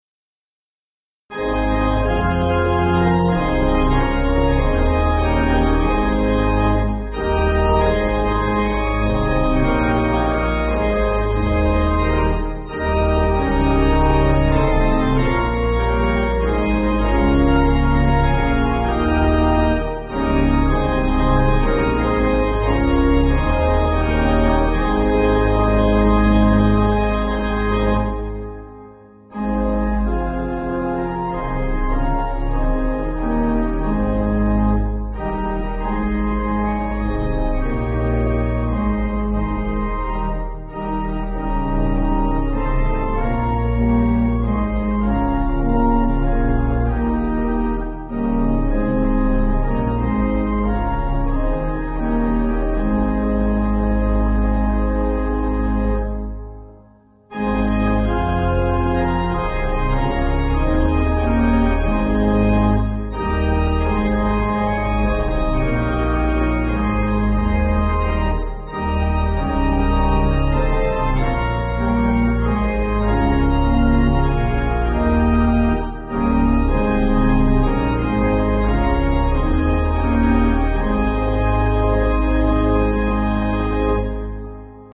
Organ
(CM)   7/Ab